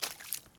tbd-station-14/Resources/Audio/Effects/Footsteps/puddle3.ogg at d1661c1bf7f75c2a0759c08ed6b901b7b6f3388c
puddle3.ogg